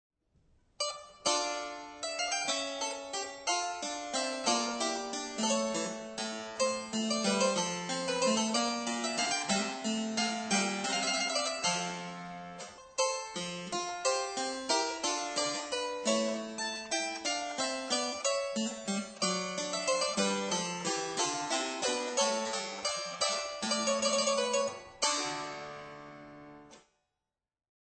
EPINETTE ITALIENNE
Elles ont un point de pincement plus près du chevalet que les virginals, ce qui leur donne un son moins rond, plus dur, mais assez puissant étant donné la petitesse de la caisse.
La caisse est en cyprès, les marches sont recouvertes d'os, les feintes sont composées d'un assemblage de buis et d'ébène.
Elle a un jeu de 8 pieds.
Vous pouvez écouter cette épinette dans un Corrente de G. Frescobaldi en cliquant ici.
epitalienne.mp3